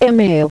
SpeakItRight allows you to fix common mispronounciations. Below is how the Speech Manager will normally mispronounce the word "email":